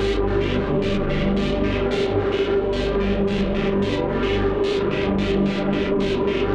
Index of /musicradar/dystopian-drone-samples/Tempo Loops/110bpm
DD_TempoDroneC_110-G.wav